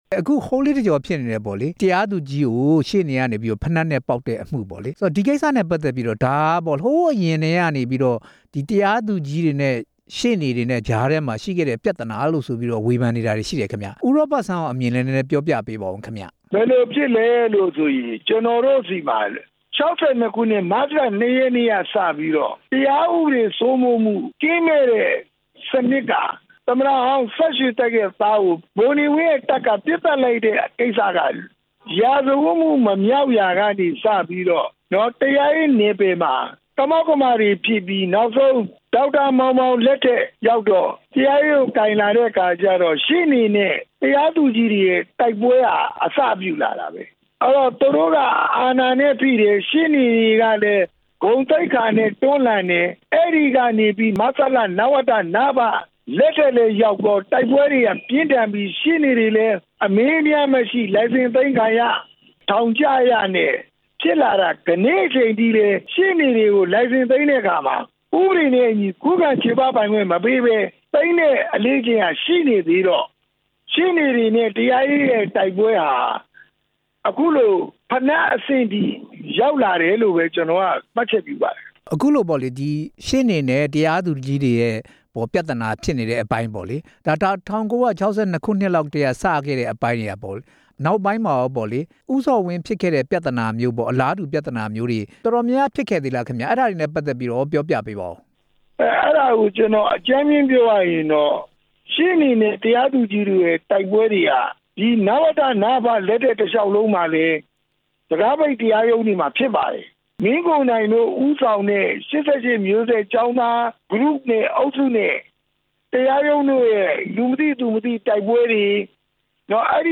တရားသူကြီးကို ဖိနပ်နဲ့ ပစ်ပေါက်ခဲ့တဲ့အမှု မေးမြန်းချက်